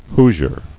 [Hoo·sier]